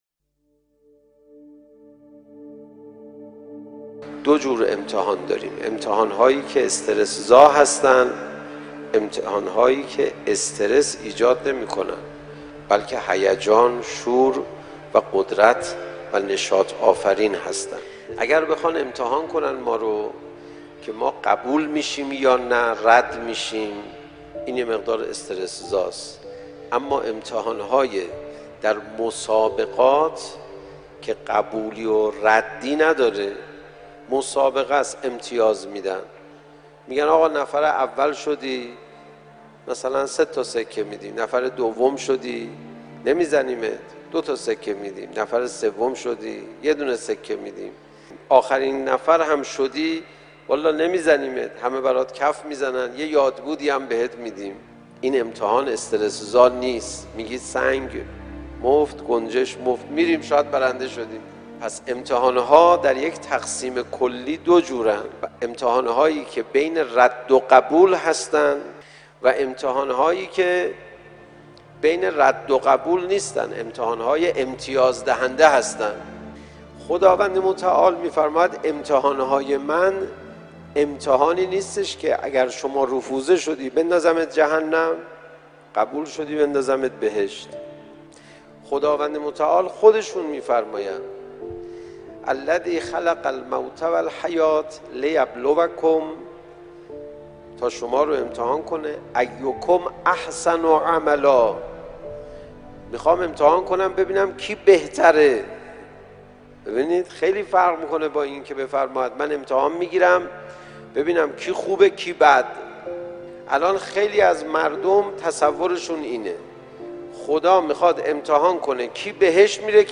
شناسنامه : تولید : بیان معنوی زمان : 05:25 منبع : مشهد - رواق امام خمینی(ره) دریافت با کیفیت (پایین(15مگابایت) | متوسط(23مگابایت) | بالا(50مگابایت) | صوت ) آپارات | یوتیوب متن: دو جور امتحان داریم؛ امتحان‌هایی که استرس‌زا هستند، امتحان‌هایی که استرس ایجاد نمی‌کنند، بلکه هیجان، شور و قدرت و نشاط‌آفرین هستند.